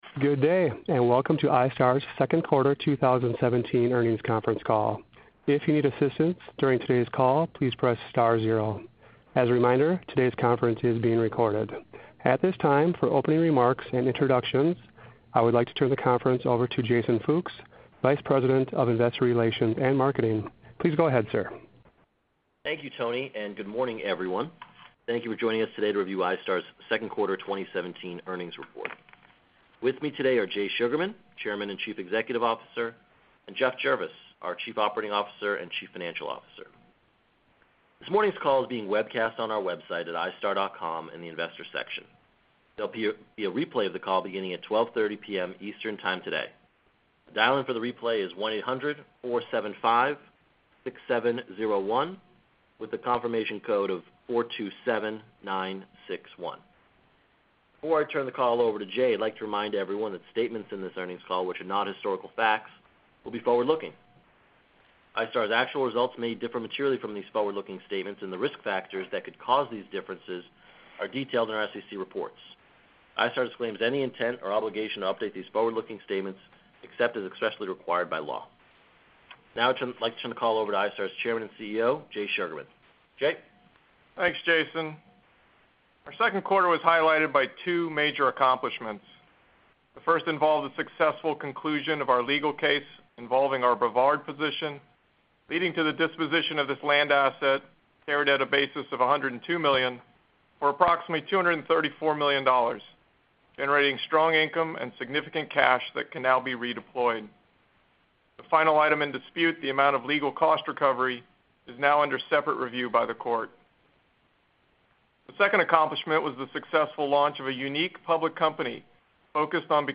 Q2 2017 iStar Earnings Conference Call | iStar